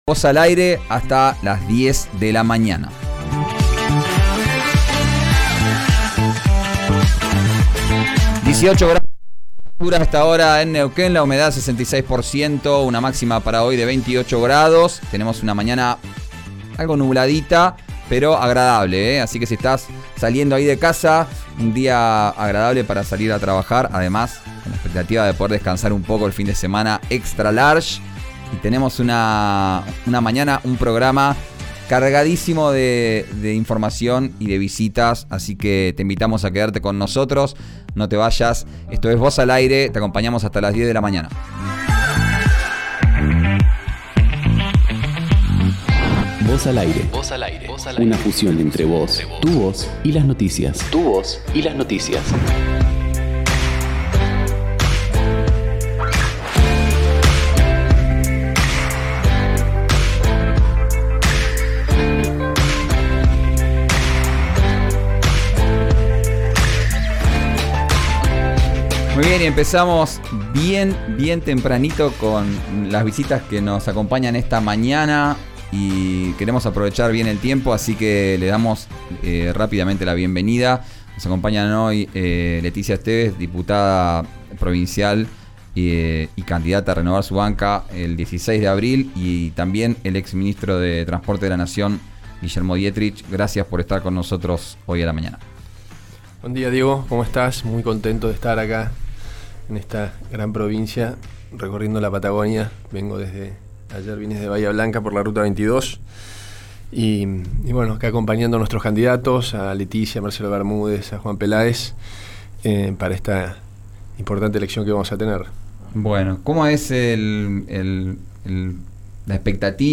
Guillermo Dietrich, exministro de Transporte de la Nación y Leticia Esteves, candidata a renovar su banca en la Legislatura provincial, visitaron el estudio de RÍO NEGRO RADIO.